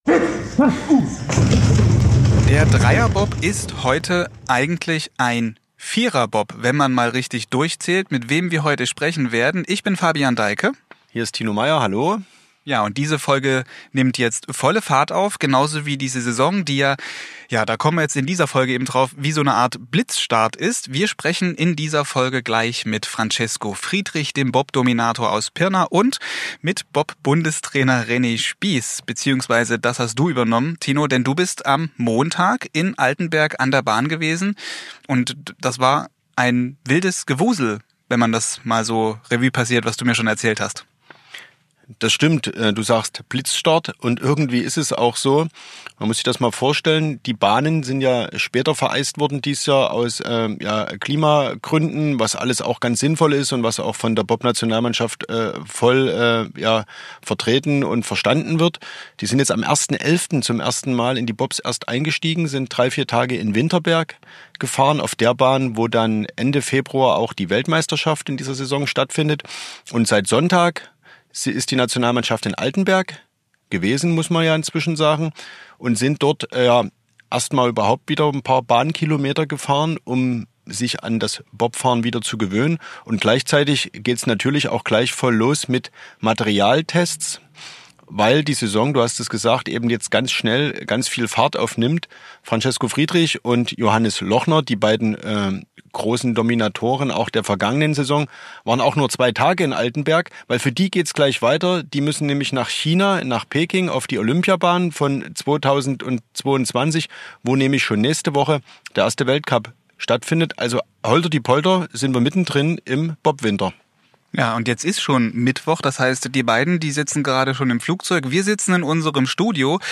und klingt dabei entspannt. Die Energie- und Umweltdebatte sei am Bobsport nicht vorbeigegangen.